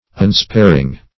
unsparing - definition of unsparing - synonyms, pronunciation, spelling from Free Dictionary
Unsparing \Un*spar"ing\, a. [Pref. un- not + sparing, p. pr. of